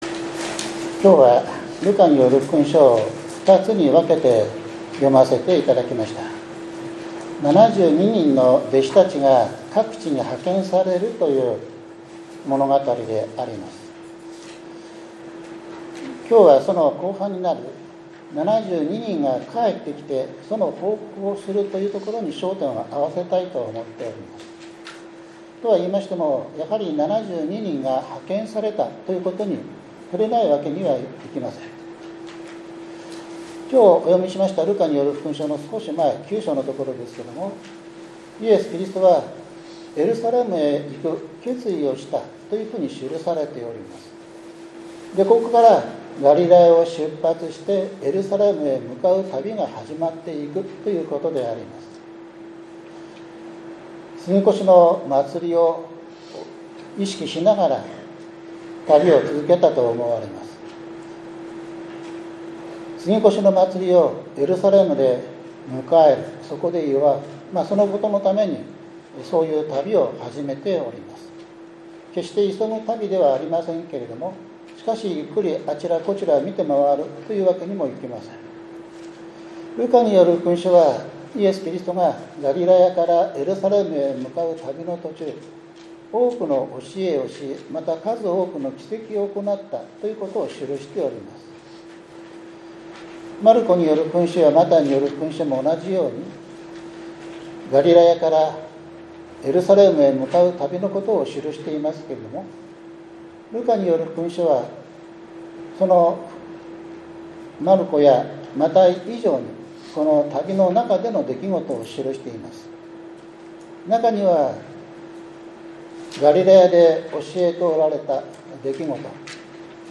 ７月６日（日）主日礼拝 ダニエル書１２章１節 ルカによる福音書１０章１節～１２節、１７節～２０節